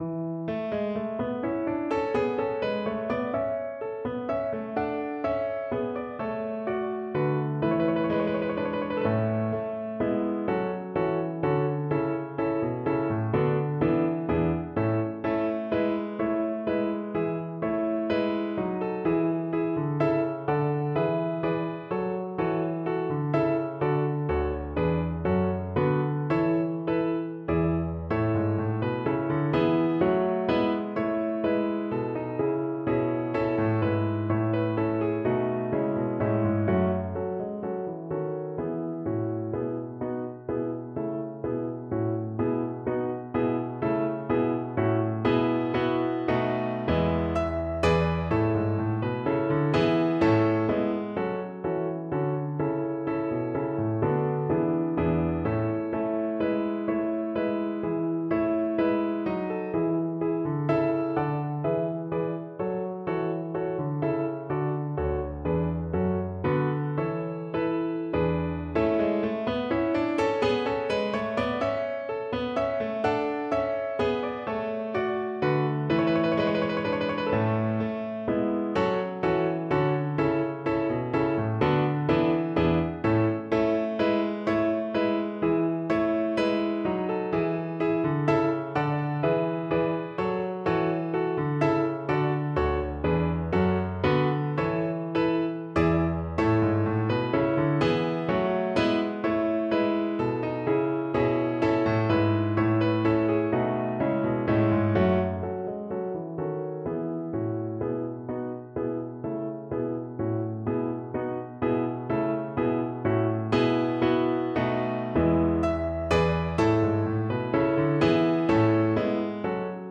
A major (Sounding Pitch) (View more A major Music for Violin )
Allegro giusto =126 (View more music marked Allegro)